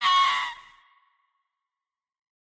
Screech Fox Sound Effect Free Download
Screech Fox